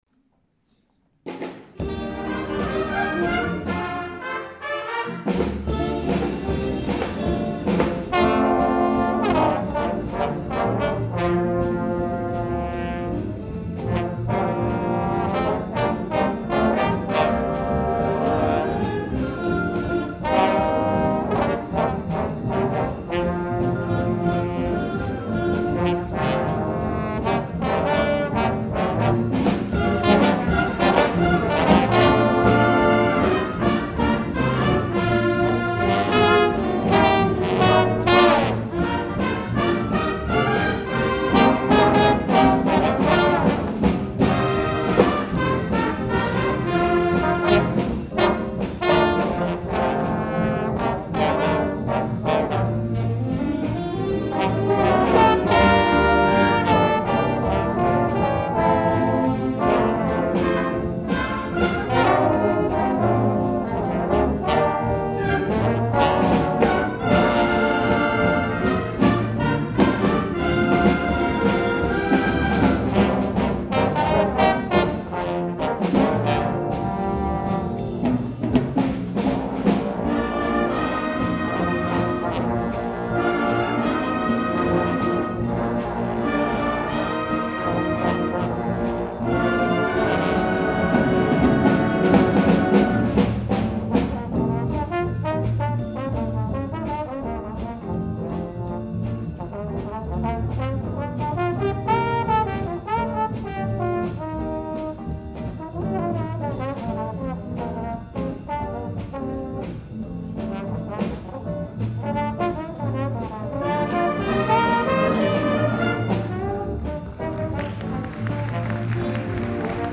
2013 Winter Concert